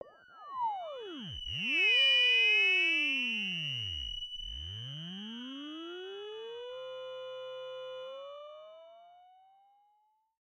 描述：通过Modular Sample从模拟合成器采样的单音。
标签： MIDI网速度21 F6 MIDI音符-90 赤-AX80 合成器 单票据 多重采样
声道立体声